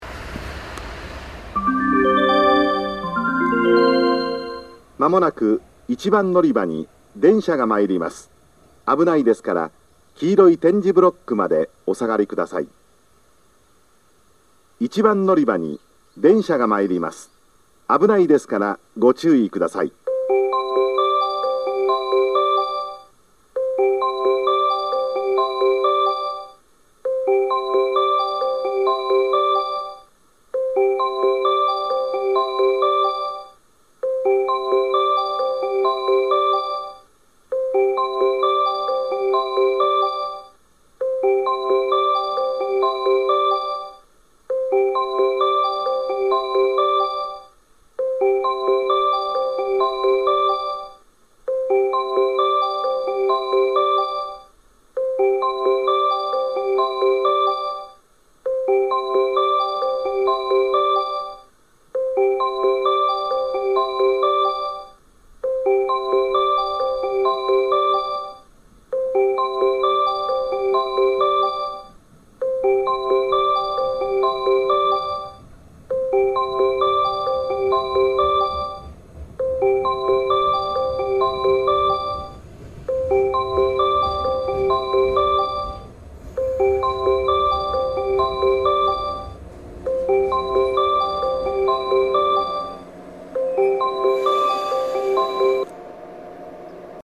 自動放送は西日本標準放送です。
（男性）
接近放送 接近メロディーは21.7コーラス鳴っています。停車列車で収録しました。
音声冒頭に2番線側の特急通過音が・・・。
どちらの番線も電車が停車するまで流れるので、停車列車の方が長く鳴ります。